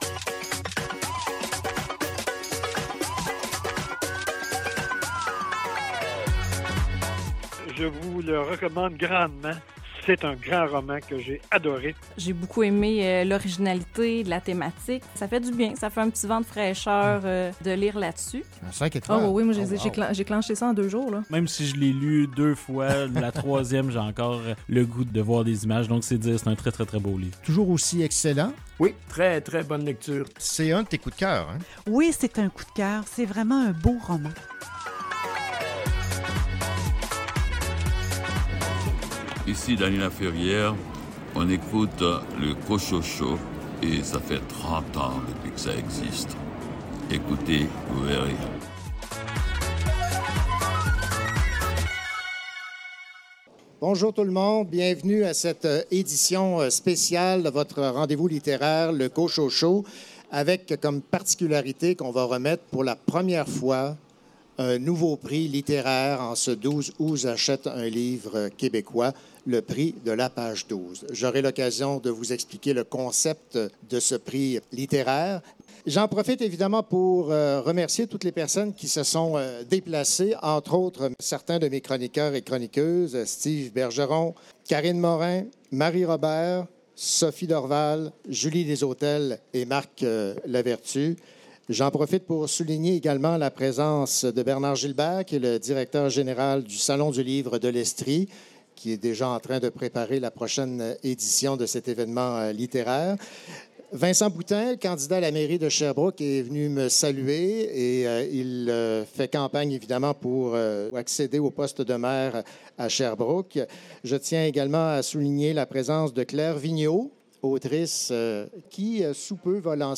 Le Cochaux show vous présente son émission spéciale enregistrée devant public consacrée à la toute première remise du Prix de la page 12.